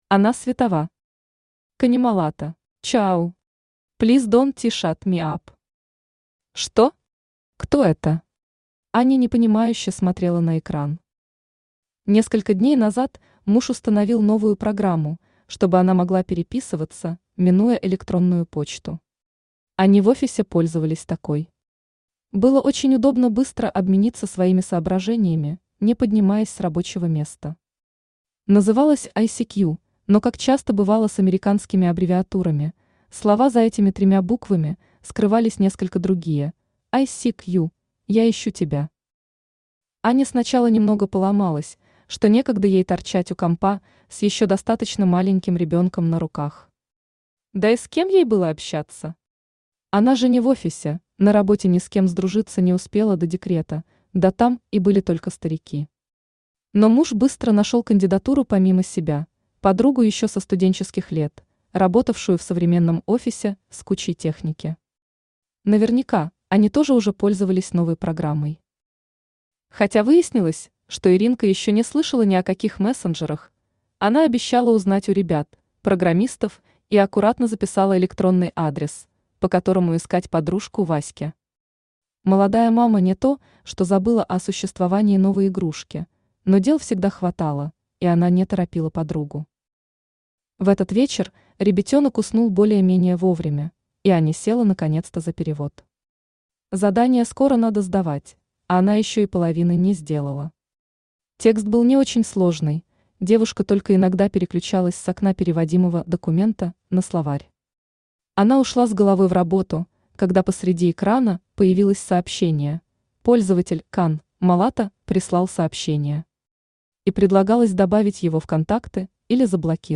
Aудиокнига Канемалато Автор Ана Светова Читает аудиокнигу Авточтец ЛитРес.